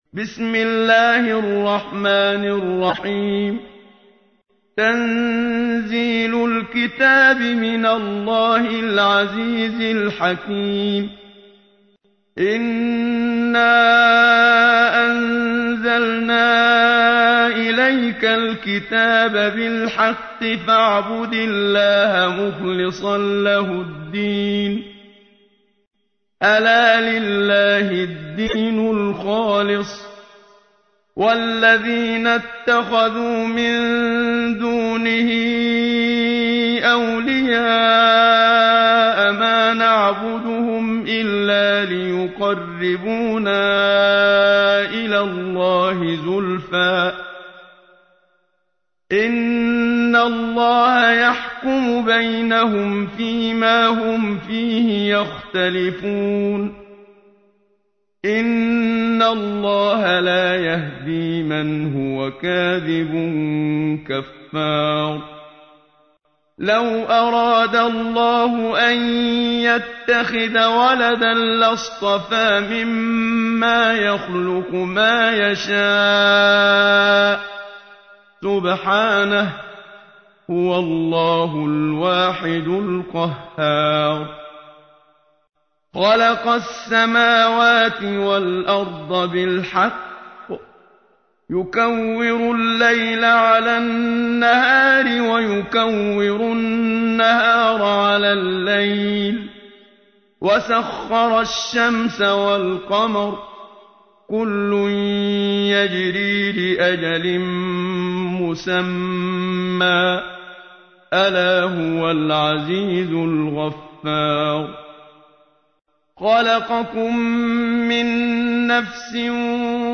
تحميل : 39. سورة الزمر / القارئ محمد صديق المنشاوي / القرآن الكريم / موقع يا حسين